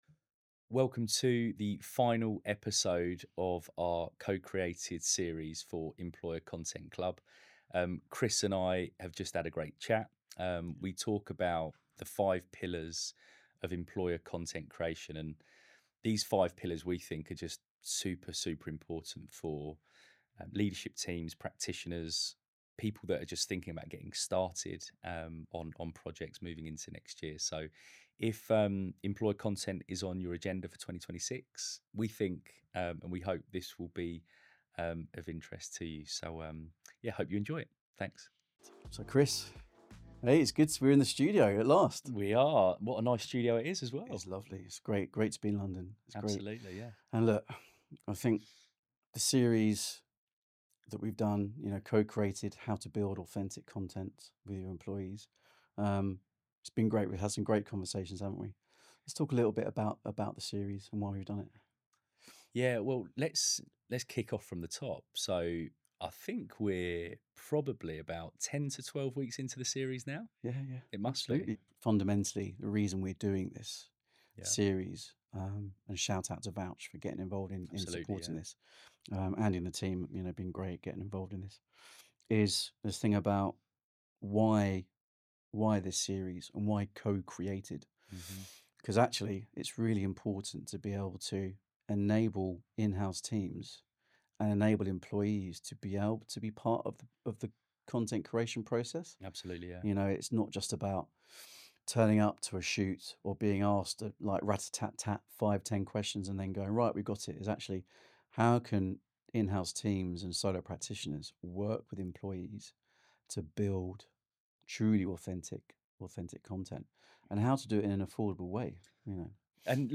This conversation looks at how employer content can create space for honest voices, surface hidden signals, and help organisations listen before people leave.